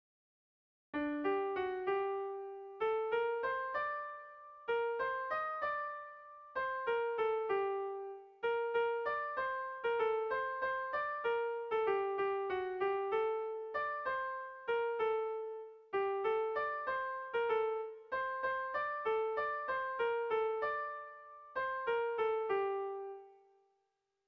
Kontakizunezkoa
AB1DB2